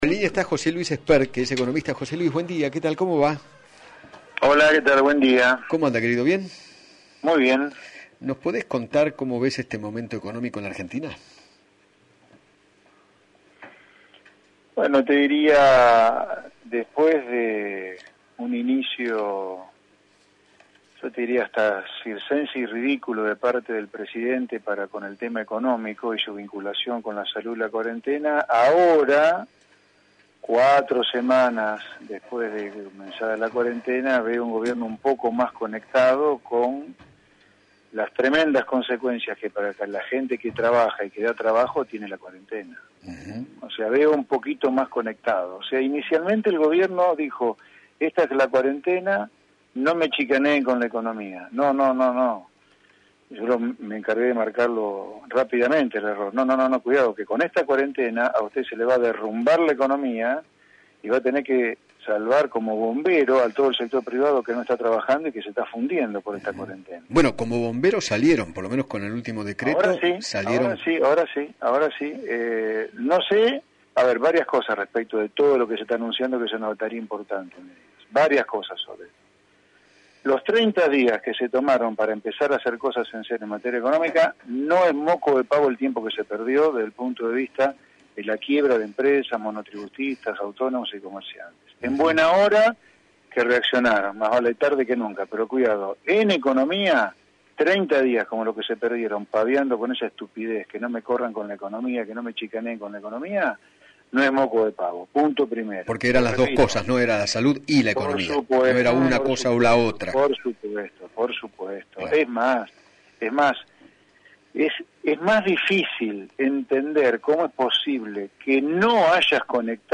José Luis Espert, economista y ex candidato a presidente, dialogó con Eduardo Feinmann sobre el rol del Estado durante la pandemia y se refirió a las medidas que tomó el Gobierno en estos días para asistir a las empresas y trabajadores.